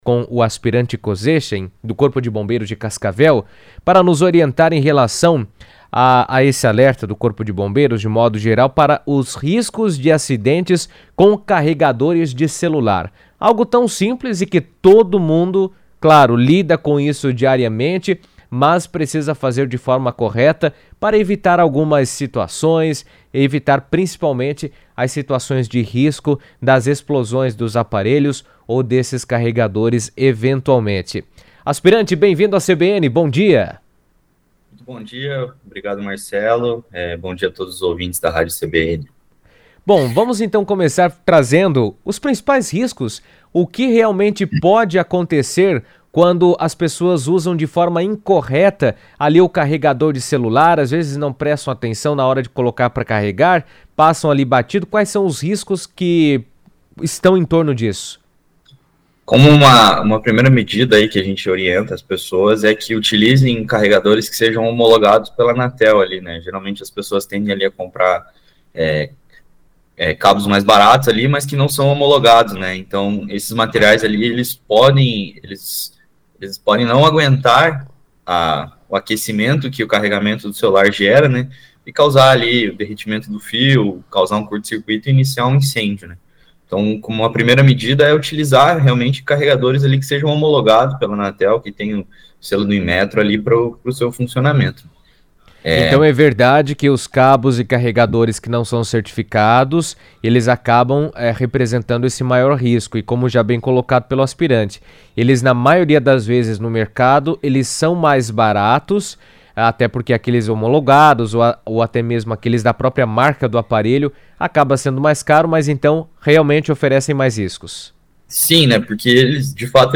O Corpo de Bombeiros alerta para os riscos de acidentes relacionados ao uso inadequado de carregadores de celular, que podem causar incêndios ou até explosões. Em entrevista à CBN